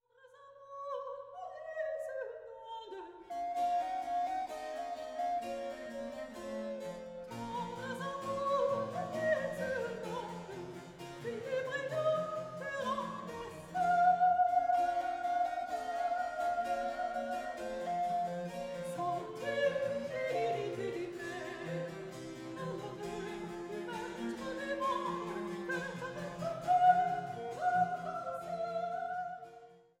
Sopran
Traversflöte
Viola da gamba
Cembalo